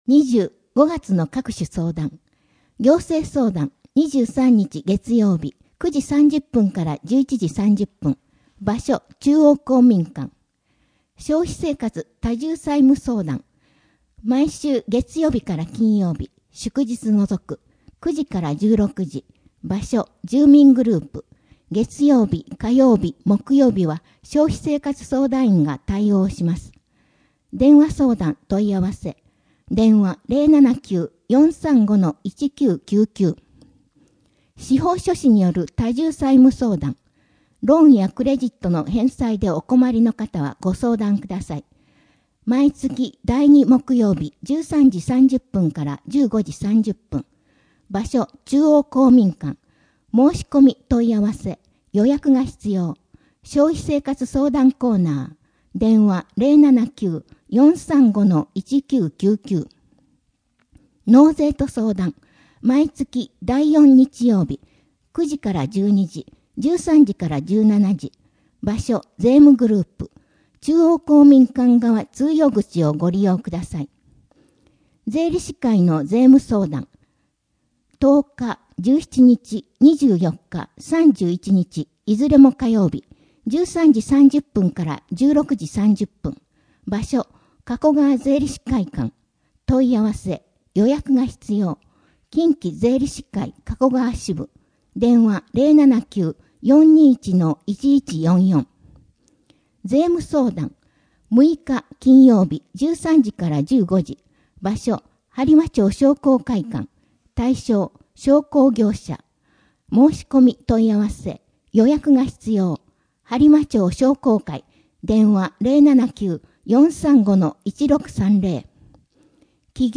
声の「広報はりま」5月号
声の「広報はりま」はボランティアグループ「のぎく」のご協力により作成されています。